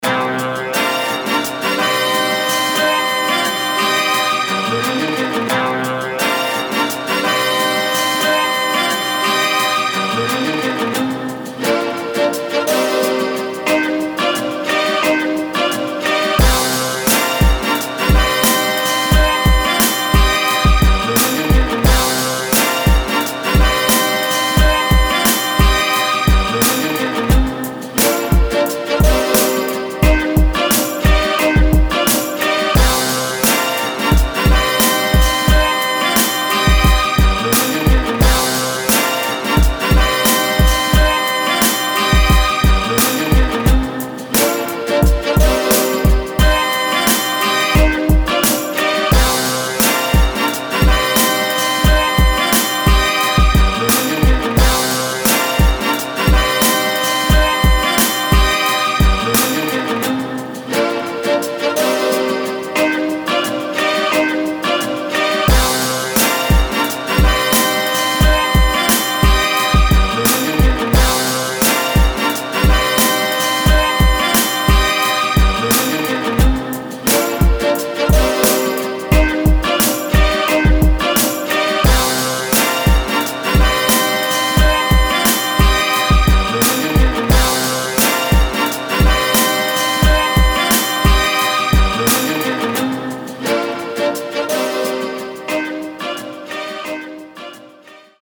Instrumental Album